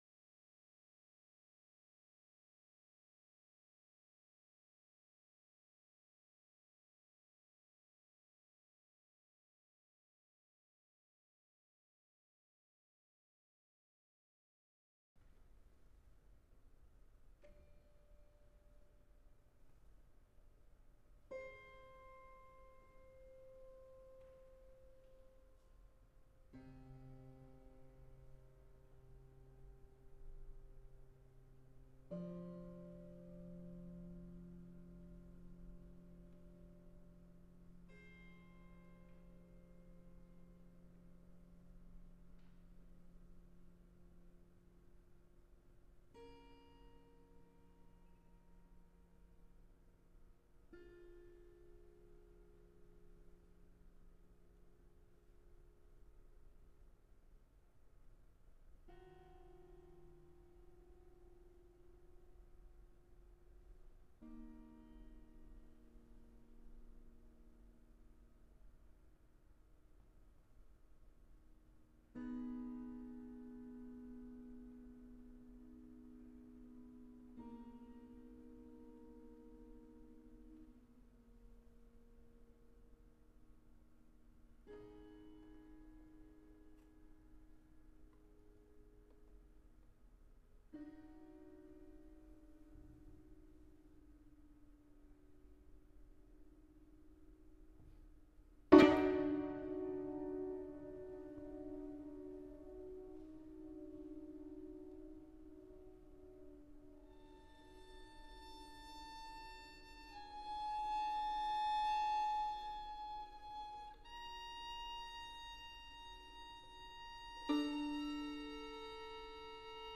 Violin
Piano
St John’s Smith Square London
Live performance – 15th  November 2018